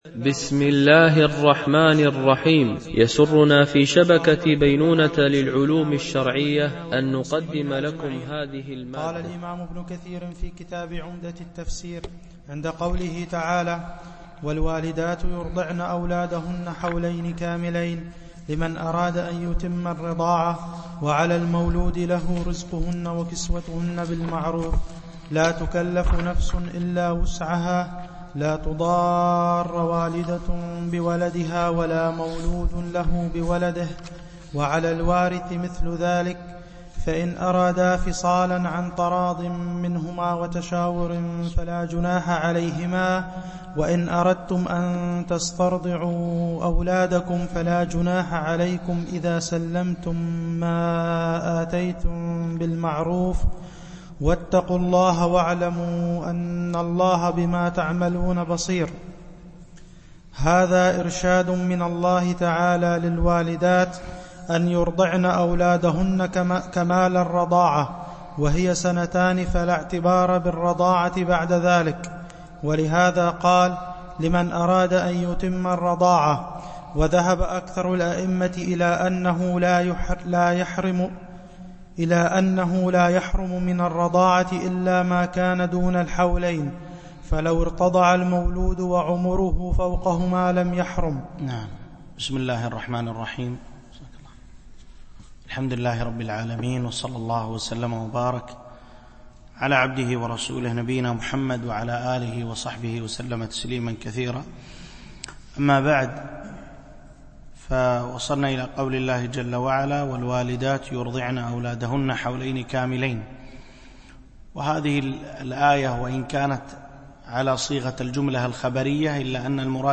شرح مختصر تفسير ابن كثير(عمدة التفسير) الدرس 38 (سورة البقرة الآية 233-237)
Mono